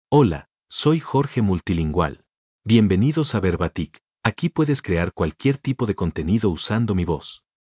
Jorge Multilingual — Male Spanish AI voice
Jorge Multilingual is a male AI voice for Spanish (Mexico).
Voice sample
Listen to Jorge Multilingual's male Spanish voice.
Jorge Multilingual delivers clear pronunciation with authentic Mexico Spanish intonation, making your content sound professionally produced.